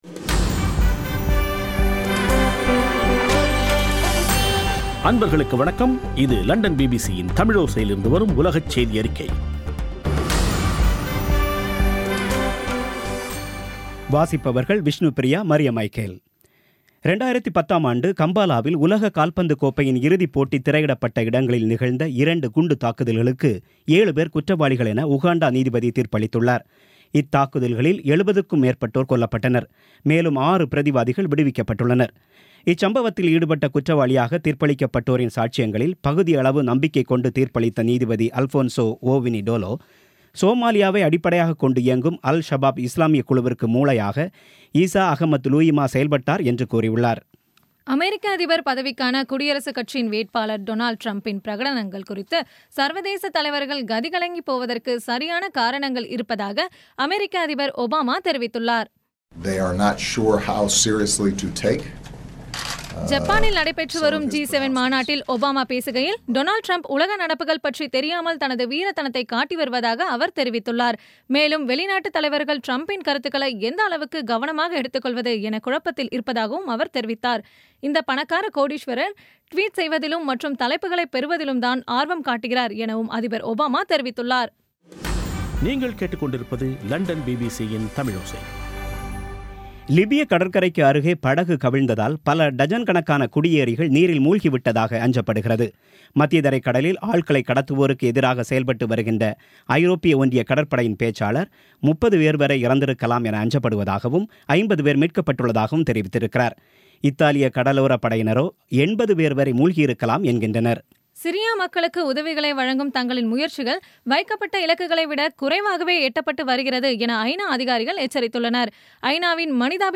இன்றைய பி பி சி தமிழோசை செய்தியறிக்கை (26/05/2016)